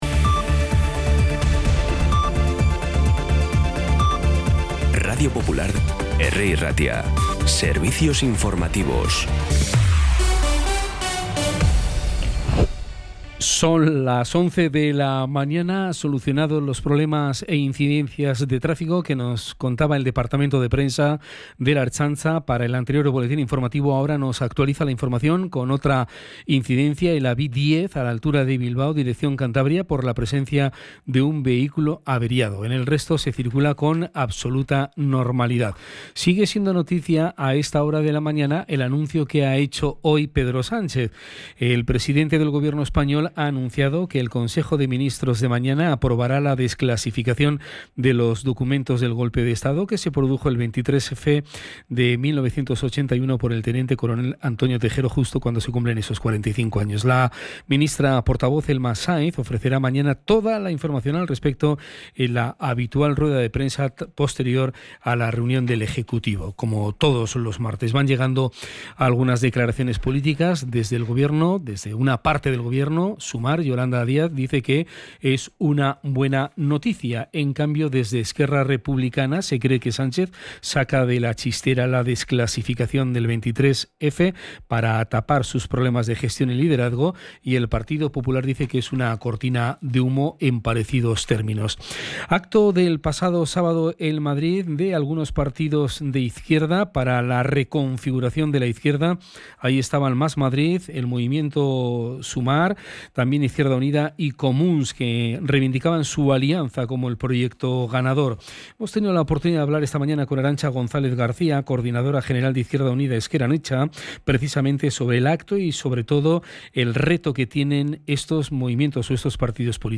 Las noticias de Bilbao y Bizkaia del 23 de febrero a las 11
Los titulares actualizados con las voces del día. Bilbao, Bizkaia, comarcas, política, sociedad, cultura, sucesos, información de servicio público.